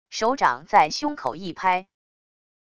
手掌在胸口一拍wav音频